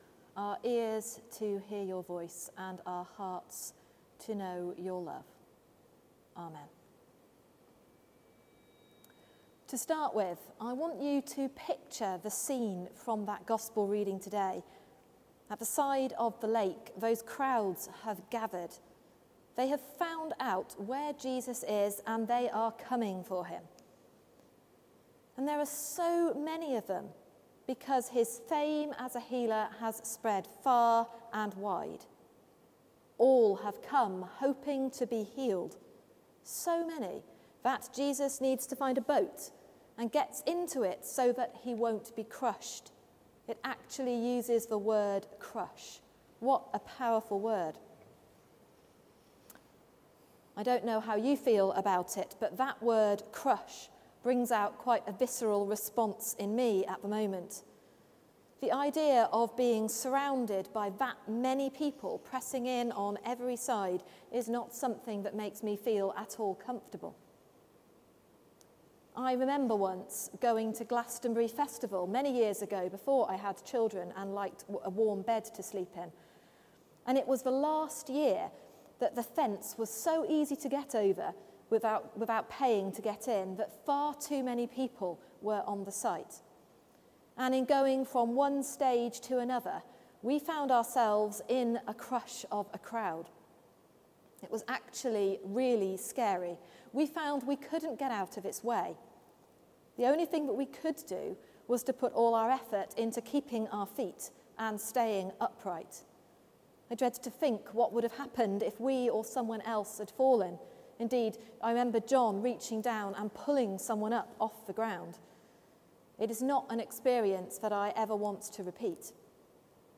Sermon: A New Song | St Paul + St Stephen Gloucester